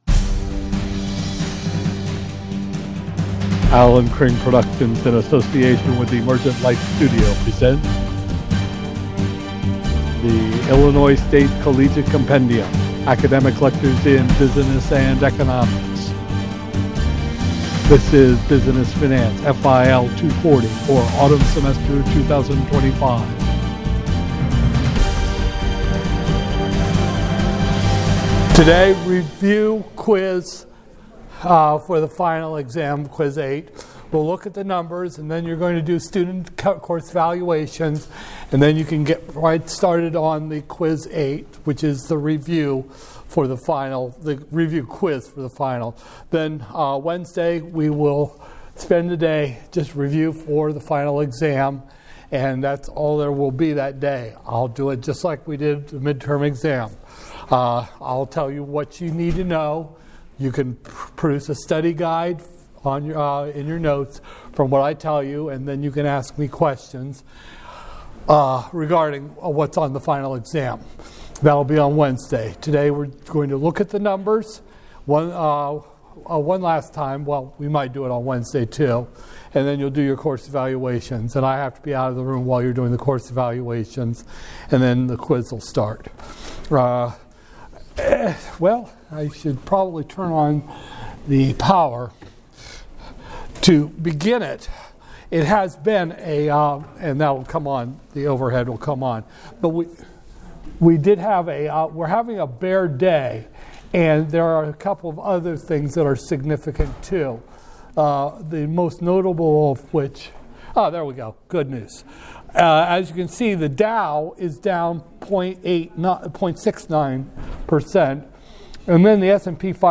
Business Finance, FIL 240-001, Spring 2025, Lecture 28